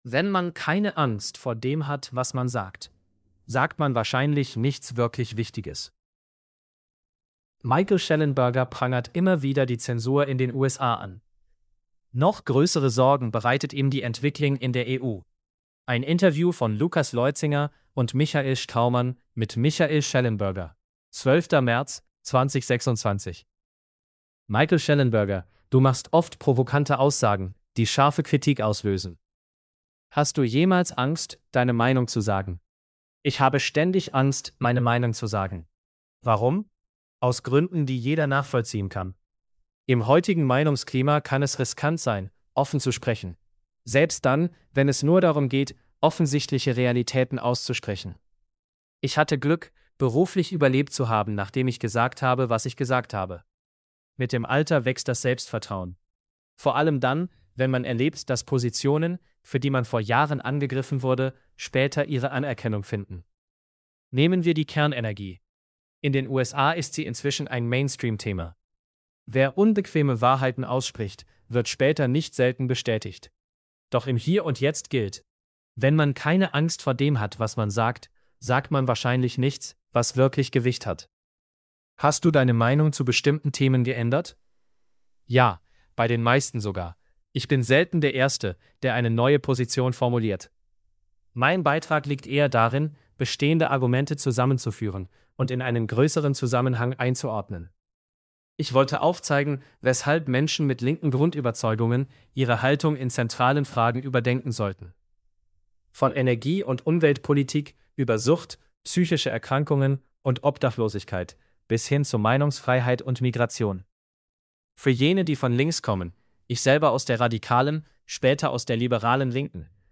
shellenberger_deutsch_male.mp3